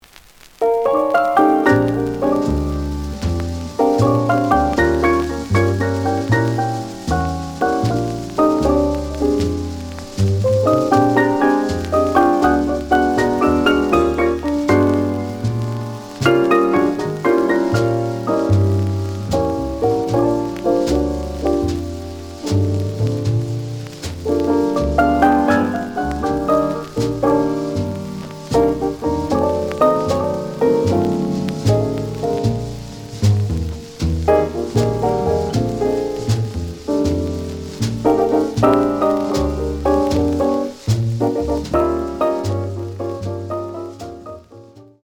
The audio sample is recorded from the actual item.
●Genre: Bop